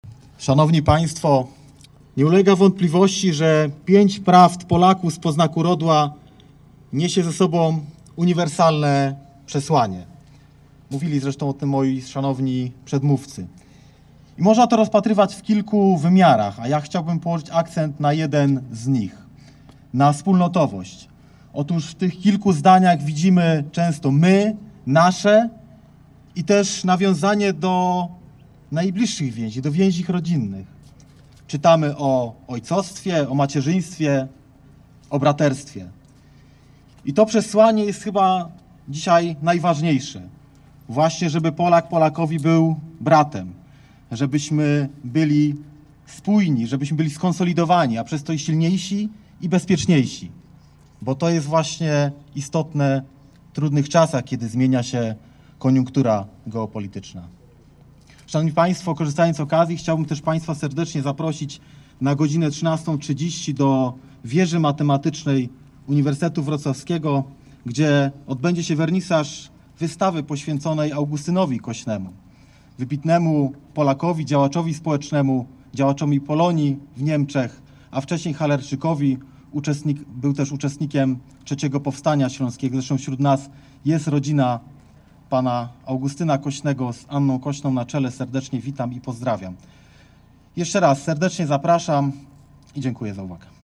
Uroczystości miały miejsce przy kościele św. Marcina na Ostrowie Tumskim we Wrocławiu.
W czasie uroczystości głos zabrali:
dr hab. Kamil Dworaczek – Dyrektor IPN we Wrocławiu